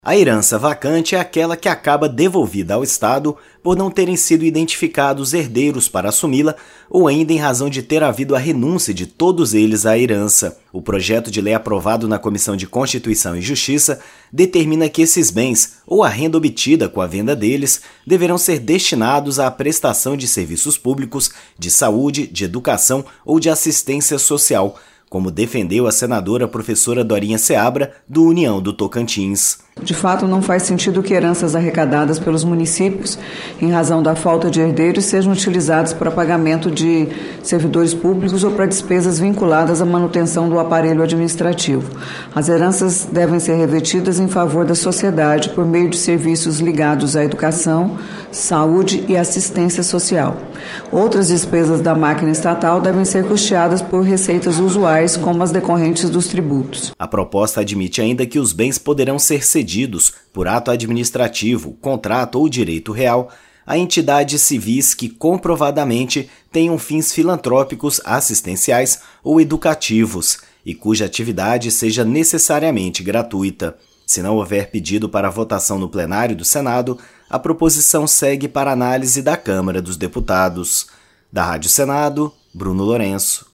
Bens poderão ainda ser cedidos a entidades filantrópicas, conforme explicou a relatora, senadora Dorinha Seabra (União-TO).